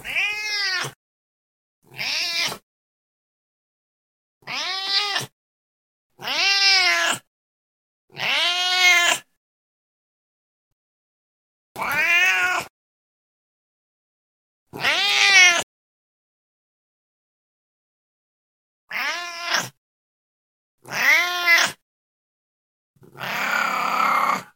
人声老猫叫
描述：人声老猫叫，卡通猫叫声
标签： 呜呜叫 老猫
声道单声道